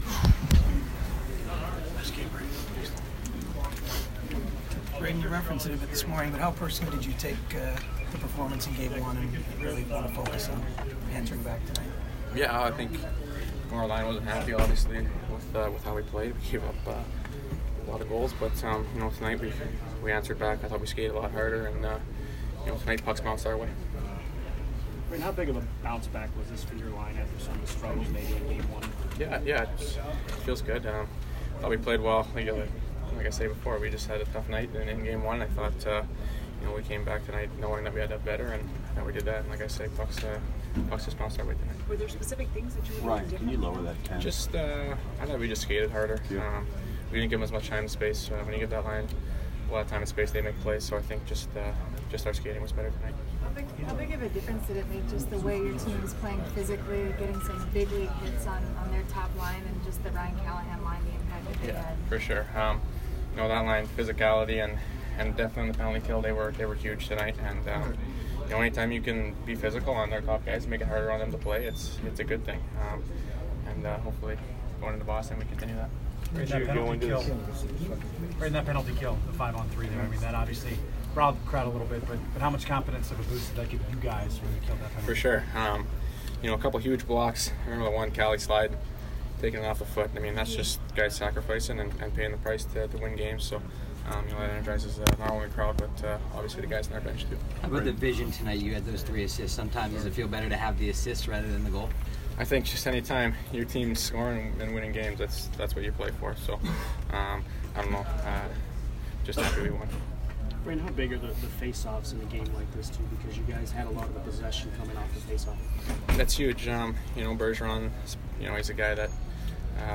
Brayden Point post-game 4/30